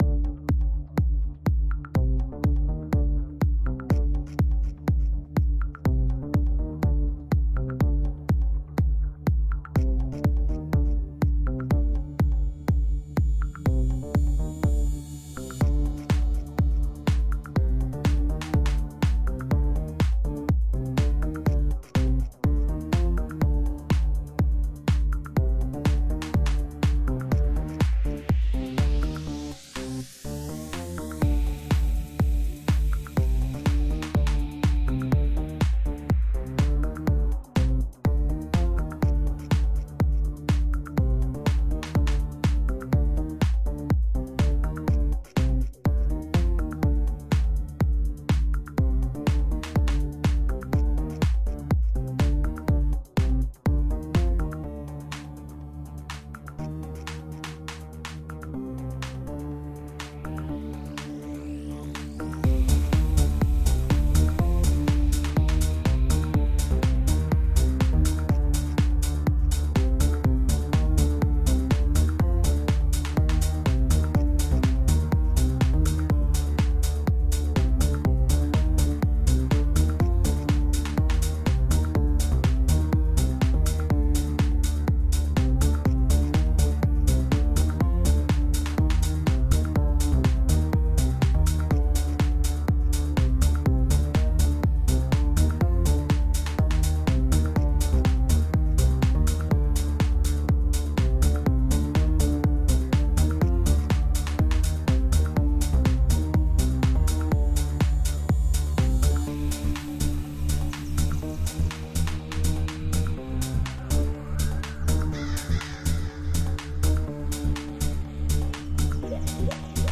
Progressive House